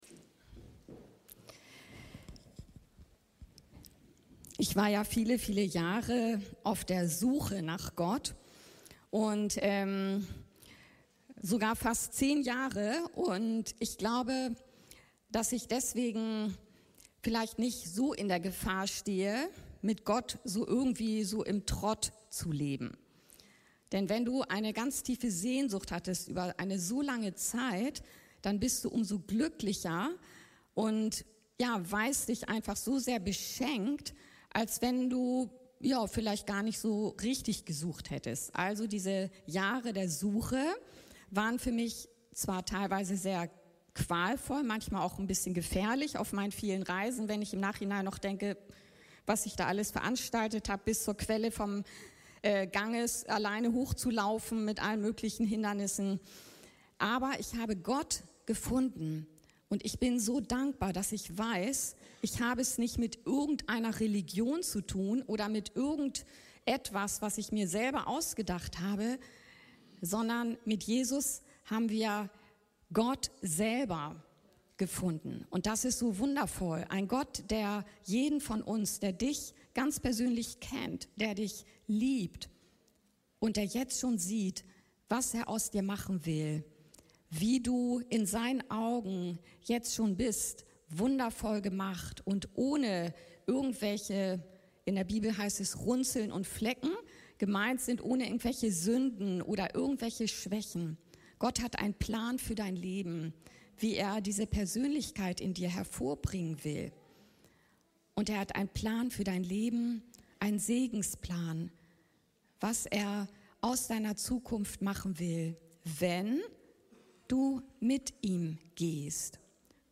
Wie höre ich Gottes Stimme? ~ Anskar-Kirche Hamburg- Predigten Podcast